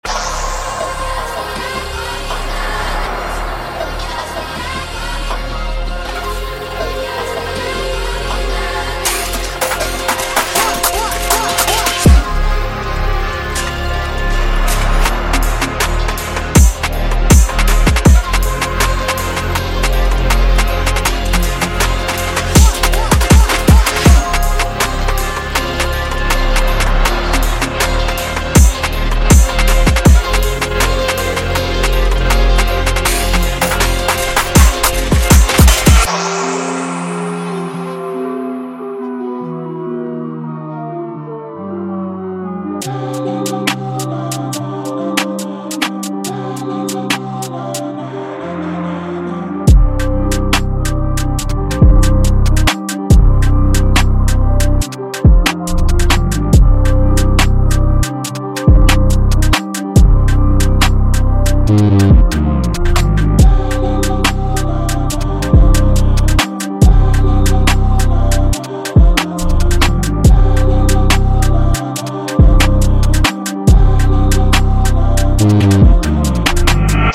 درام کیت دریل